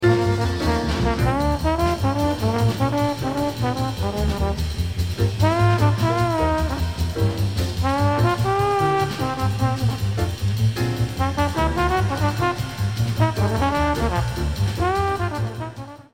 an uptempo song full of one-measure drum breaks.
trombonist